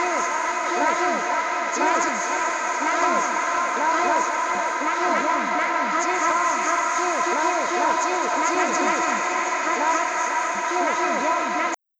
Hzモードではアンビエント系の音に向き、
またTEMPERAはBPMモードの他に「Hzモード」という周波数ベースのグラニュラー変化もできます。